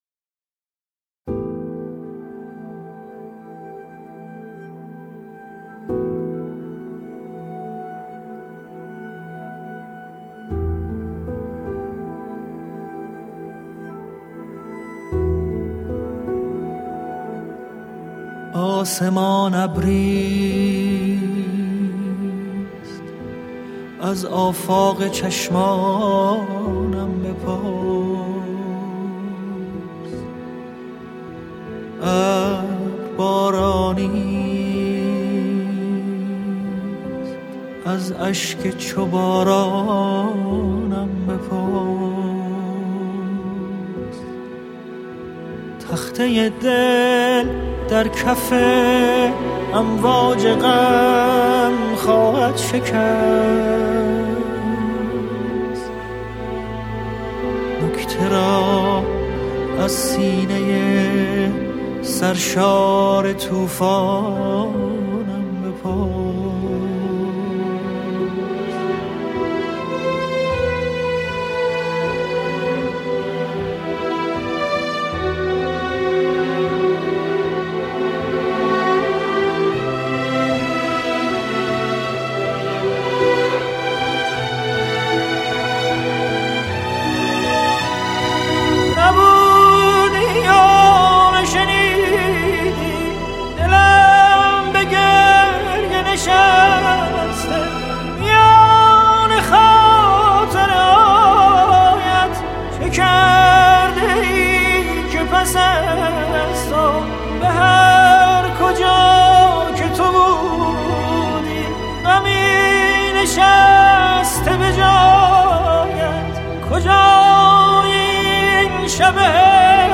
خدای صدا لطافت صدا🖤🖤🖤